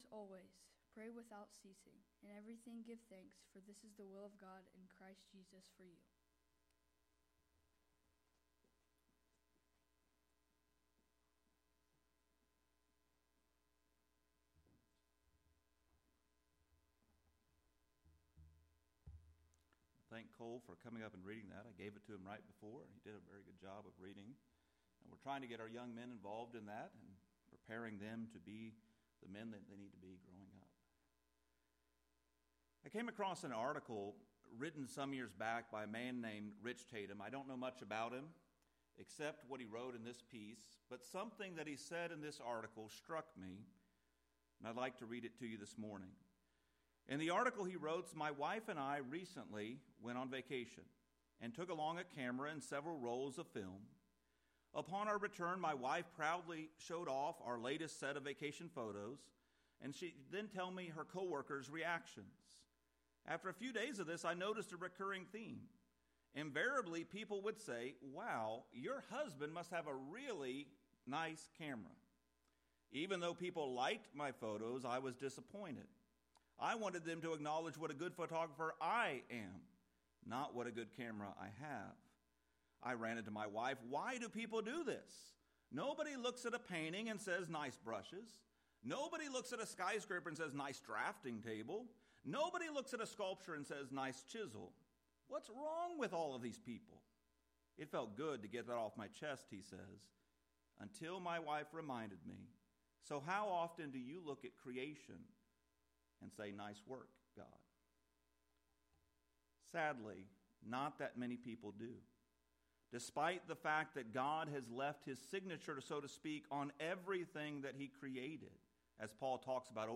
The sermon goal is call God’s people to genuine, God-centered thanksgiving—especially around the cultural Thanksgiving holiday, but more broadly as a constant Christian posture.